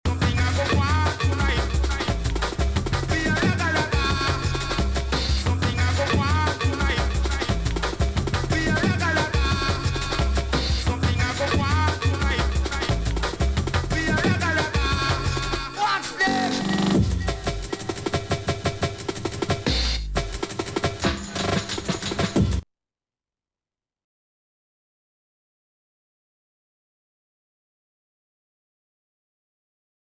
Drum & Bass / Jungle